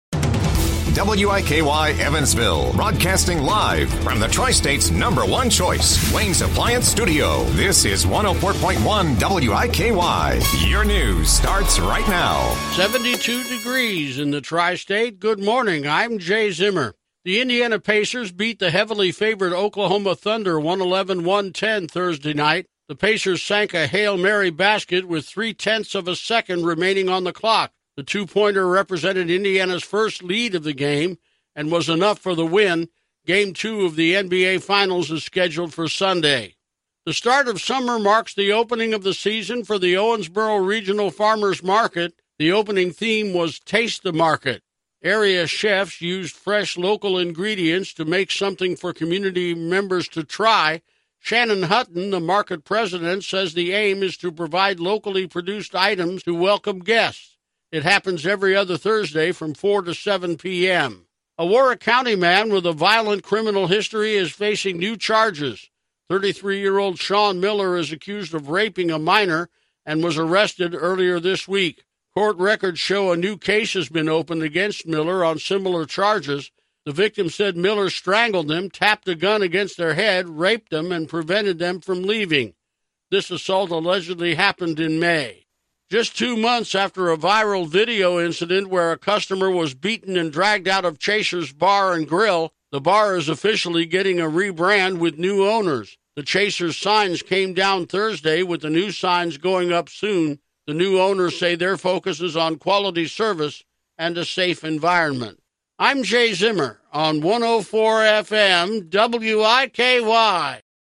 Best locally originated newscast.